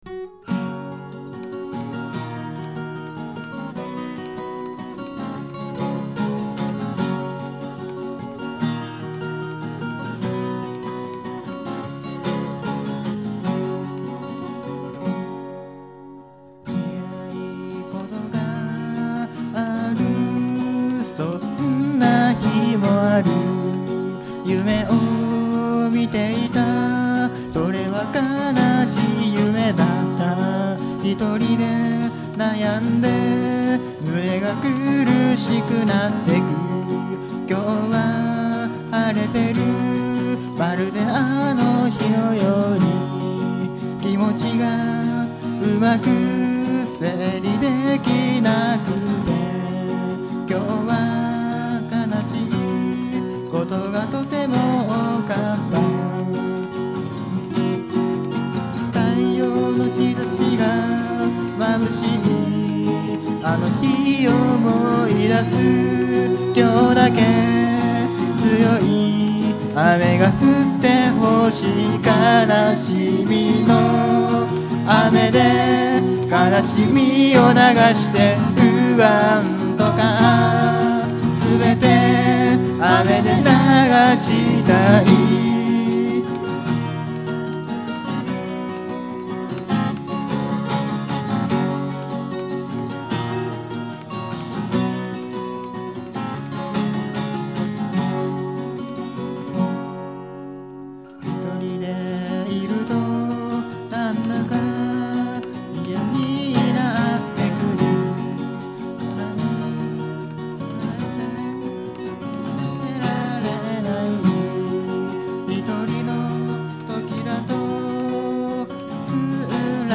Bluegrass style Folk group
録音場所: 御茶ノ水KAKADO
ボーカル、ギター
コーラス、ギター